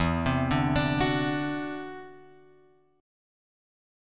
E7sus2#5 chord {0 3 0 x 3 2} chord